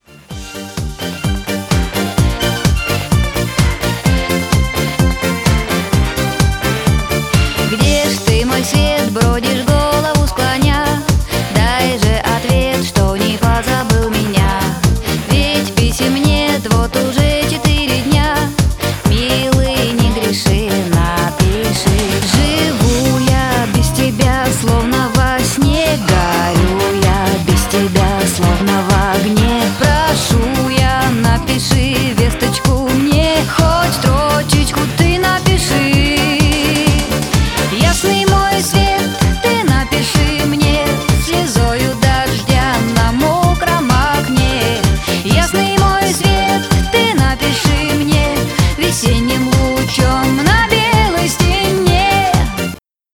Ретро рингтоны